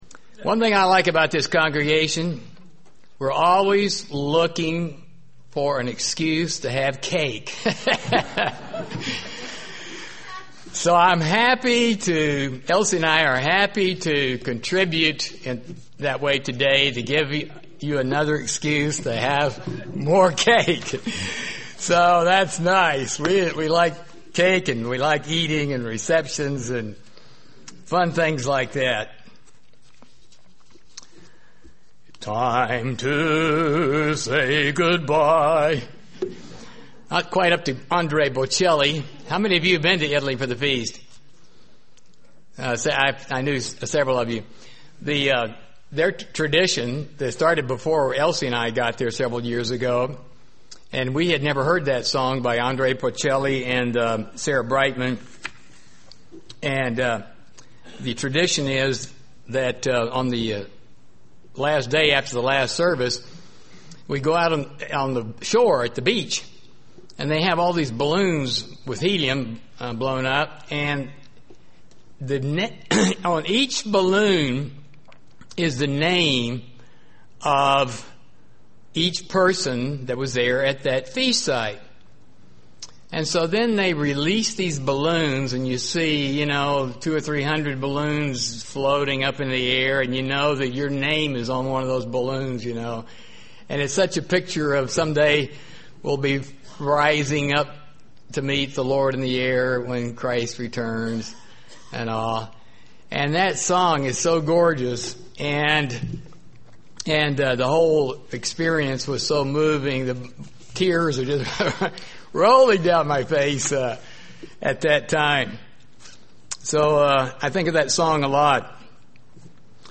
Given in Burlington, WA
UCG Sermon Studying the bible?